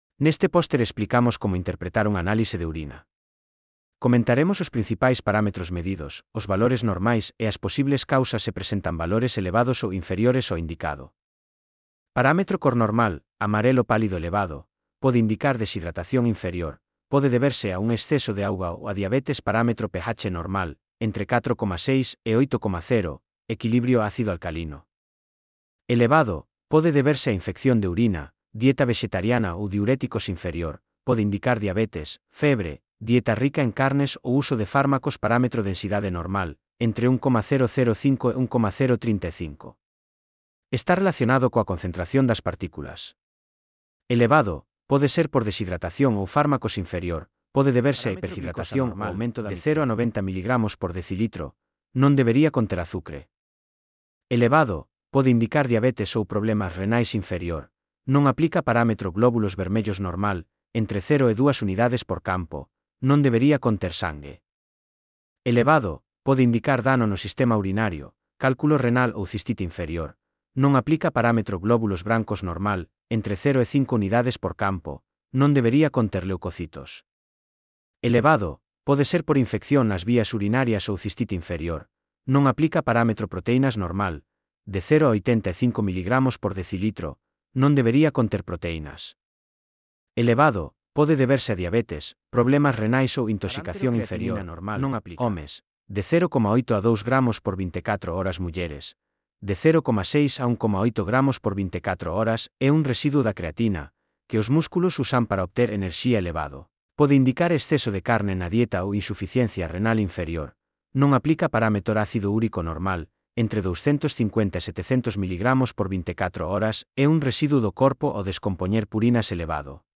Elaboración propia coa ferramenta Narakeet (CC BY-SA)